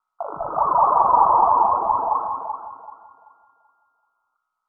DEEPBREATH.wav